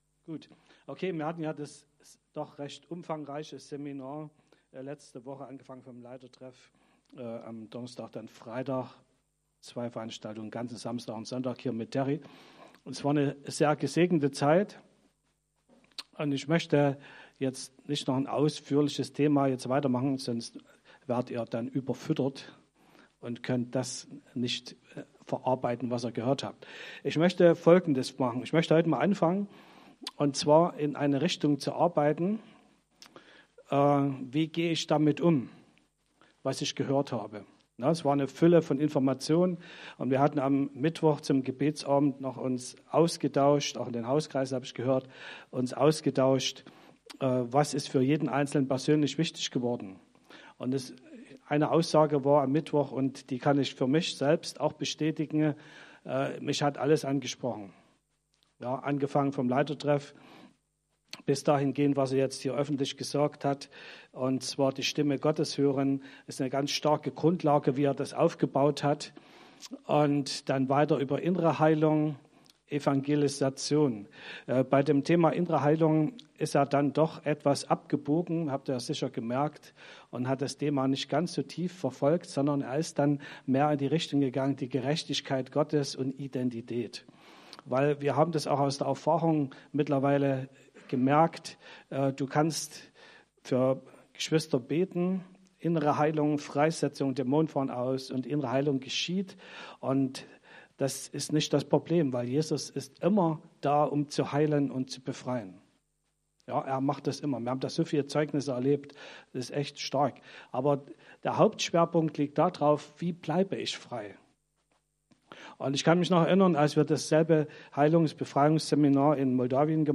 Predigten chronologisch sortiert